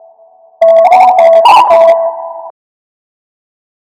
Not a very physical specimen, Hack-It used his knack for machinery to cobble together personal protection in the form of a hover drone he calls Sputter (who sounds like
Sputter.wav